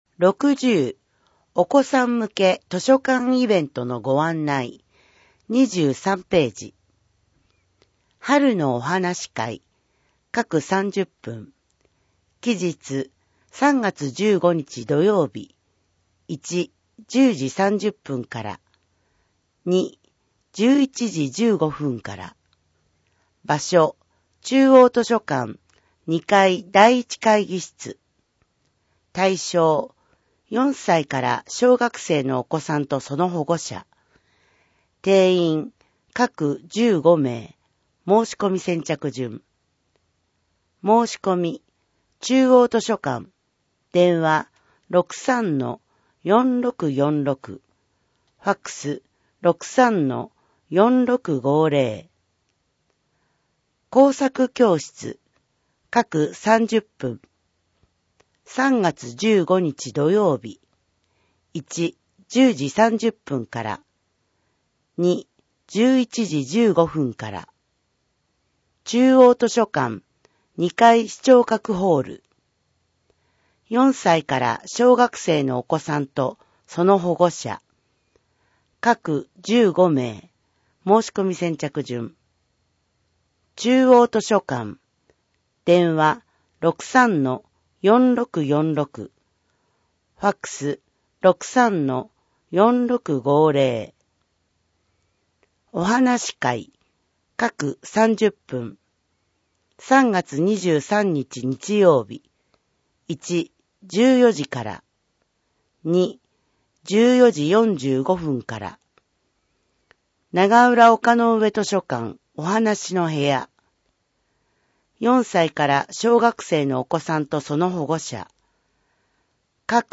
目の不自由な人などのために録音されたデイジー図書を掲載しています。